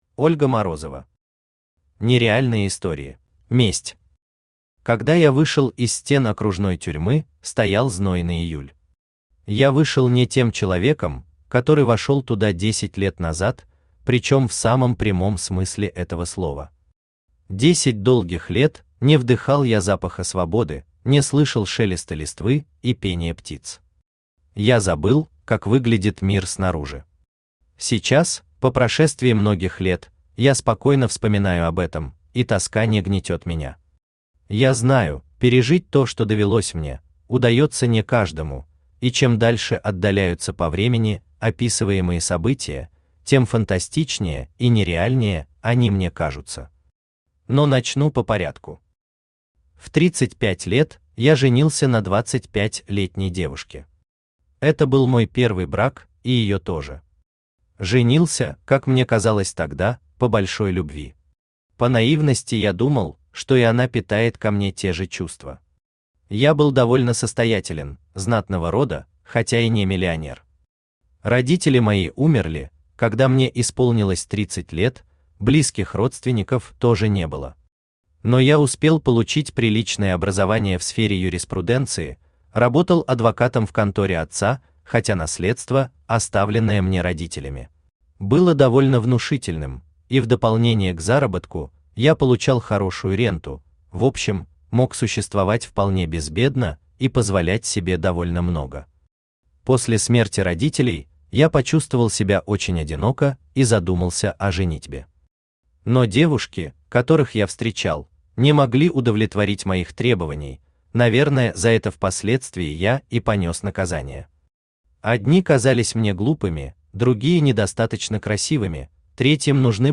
Аудиокнига Нереальные истории | Библиотека аудиокниг
Читает аудиокнигу Авточтец ЛитРес.